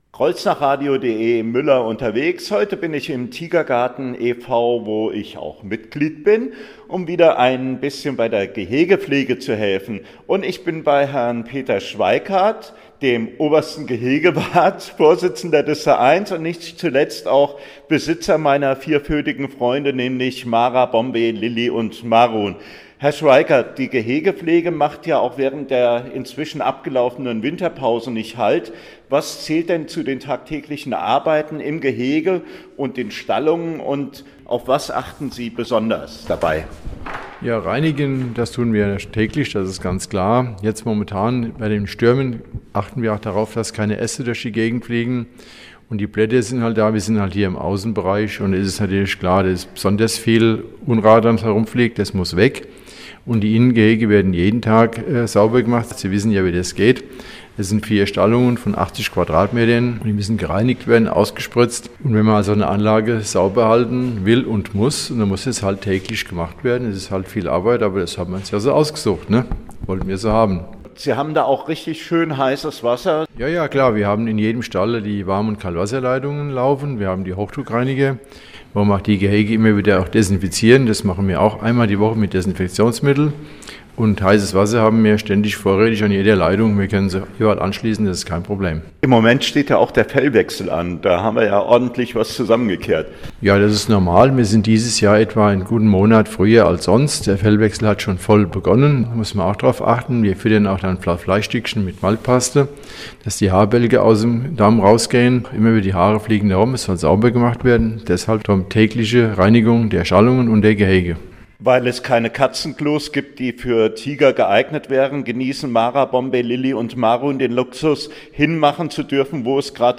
INTERVIEW…